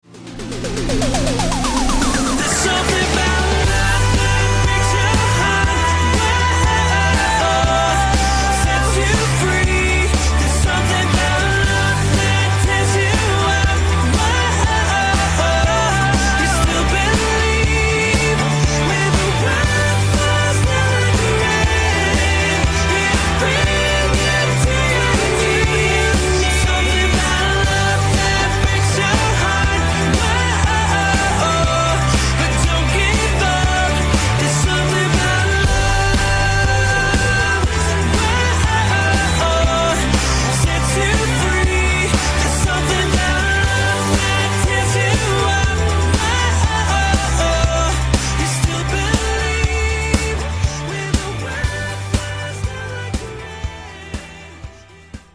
RingtoneをMP3で作ってみました。